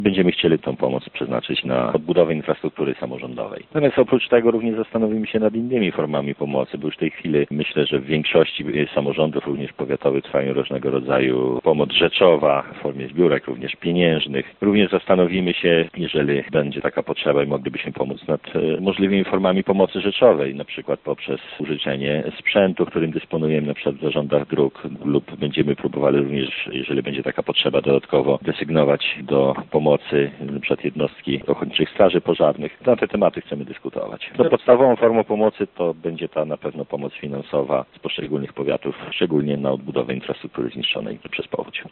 Oprócz darów rzeczowych mogą zaproponować sprzęt, dodatkowe jednostki OSP oraz pomoc finansową – informuje przewodniczący konwentu, starosta lubelski Paweł Pikula: